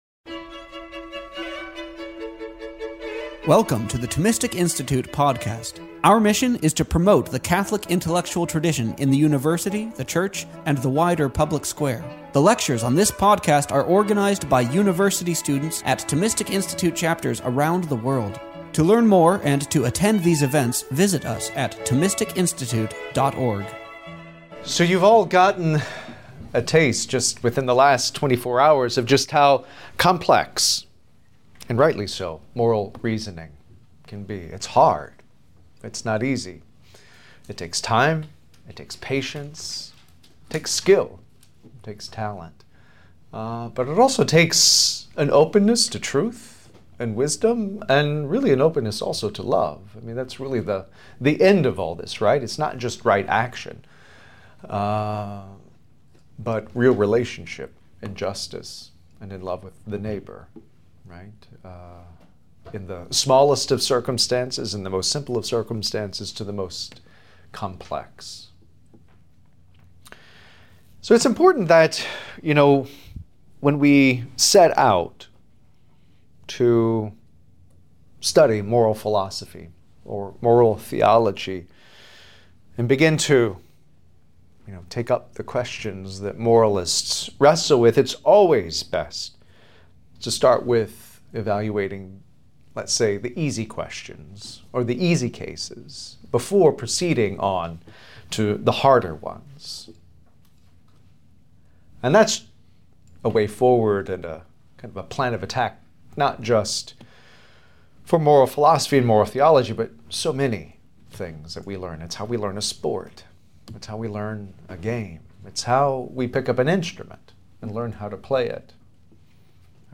This lecture was given on March 3, 2022 at the University of Alabama, Birmingham.